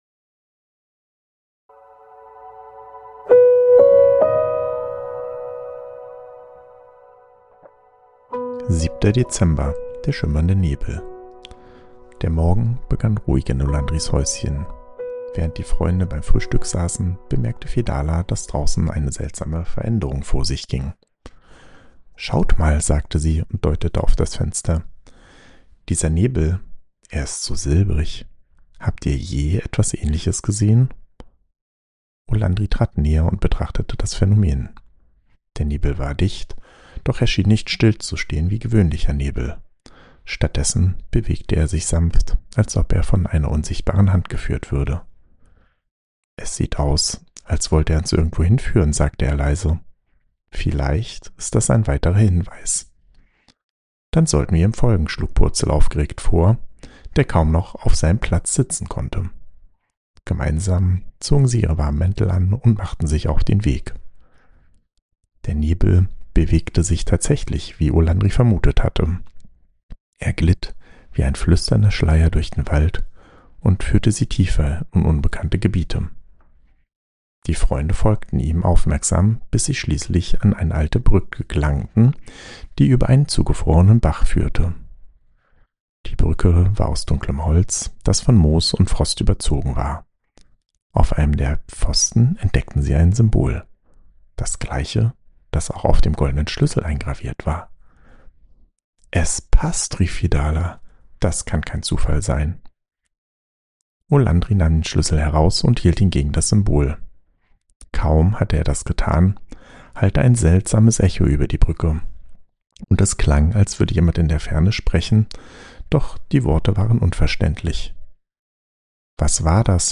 Ruhige Adventsgeschichten über Freundschaft, Mut und Zusammenhalt